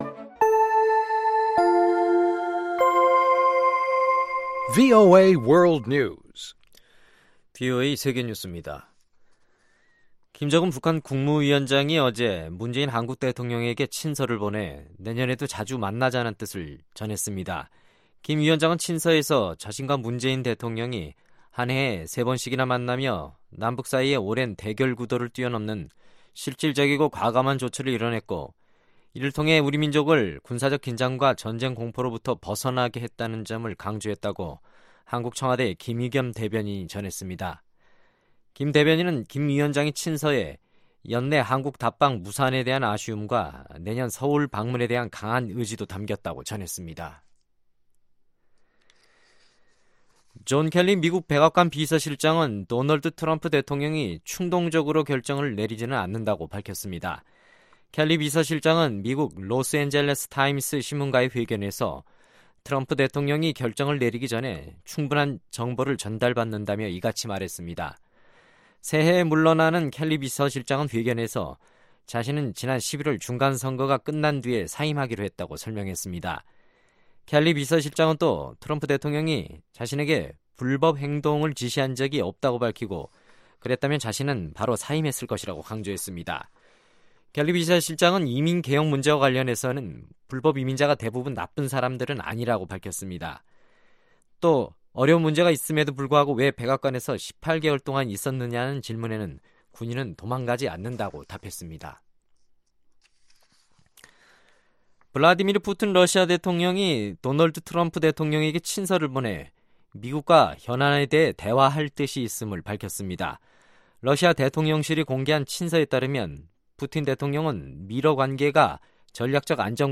VOA 한국어 간판 뉴스 프로그램 '뉴스 투데이', 2018년 1월 1일 2부 방송입니다. 미국 전직 관리들은 올해 트럼프 행정부의 대북 정책이 한반도 긴장을 완화시킨 것은 고무적이지만 미래의 불확실성은 더욱 커졌다고 평가했습니다. 남미 순방에 나서는 마이크 폼페오 미 국무장관이 현지에서 북한 문제 등을 논의할 것이라고 국무부가 밝혔습니다.